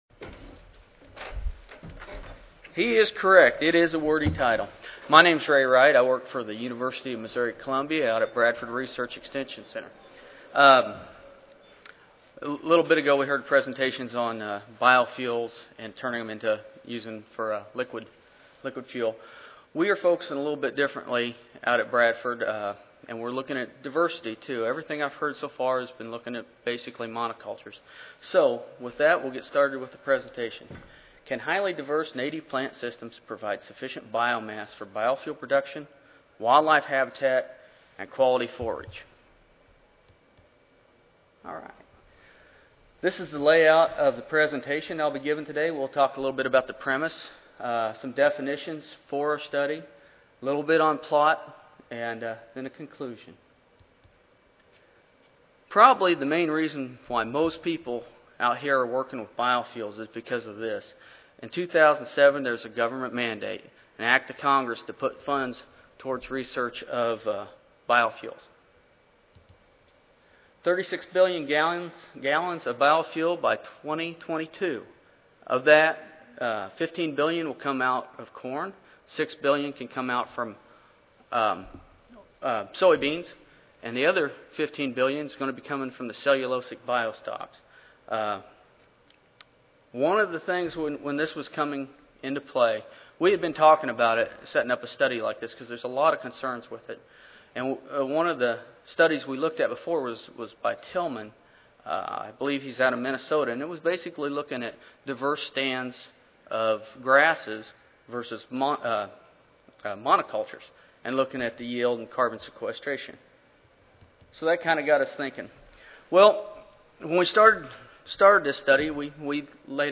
University of Missouri Recorded Presentation Audio File